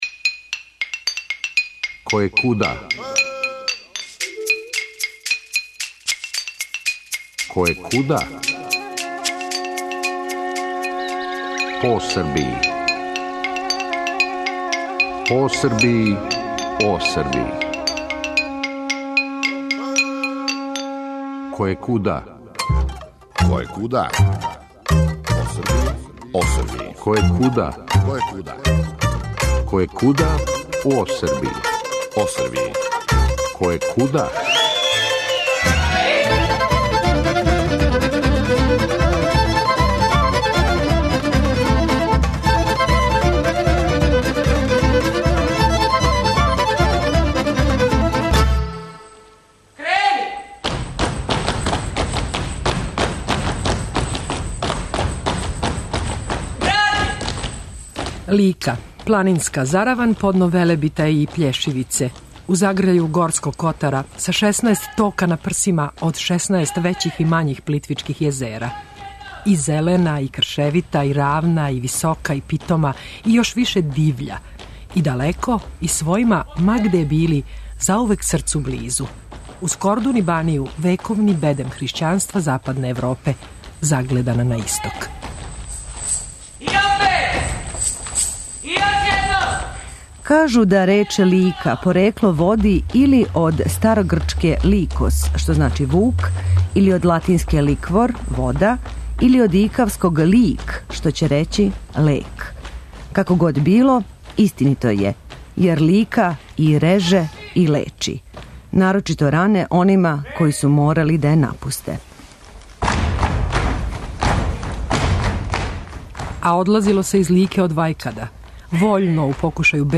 И пило се, дакако, и певало - ојкало! - и играло.